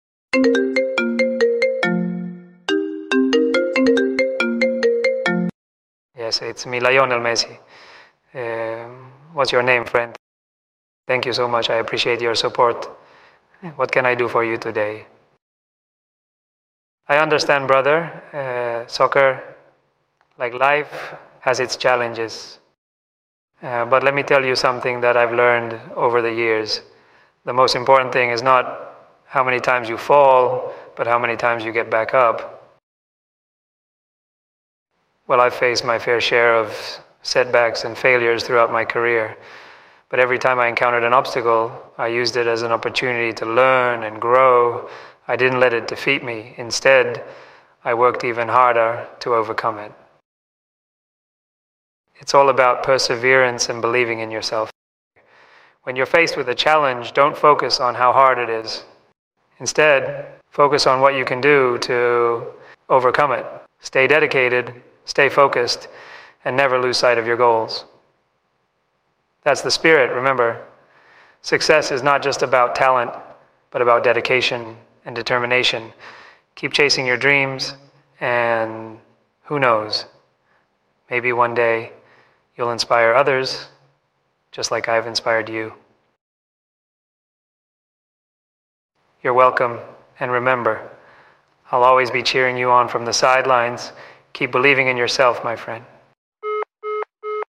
Get ready for a realistic fake call where Messi shares his secrets to success, hard work & dedication!
It feels so real, you’ll think it’s actually him!